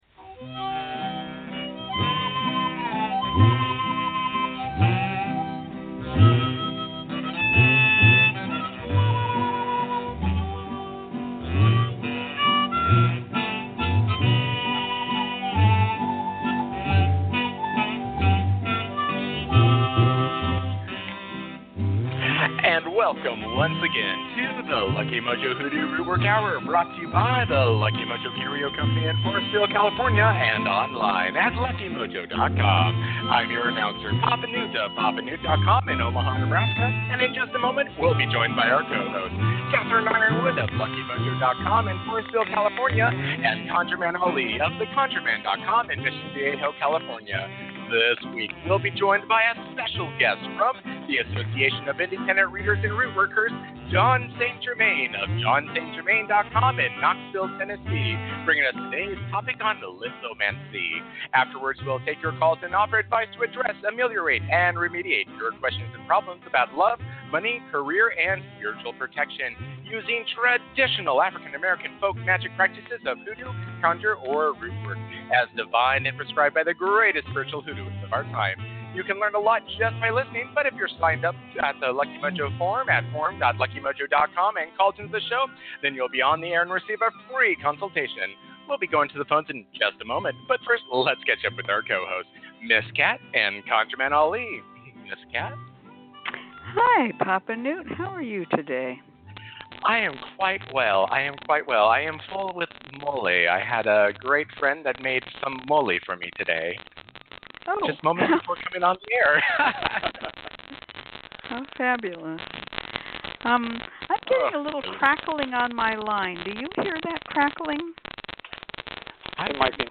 tutorial
Yes, static
Like a radio...cozy sound
It's a LOT of static